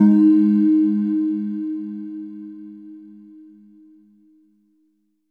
LEAD G#2.wav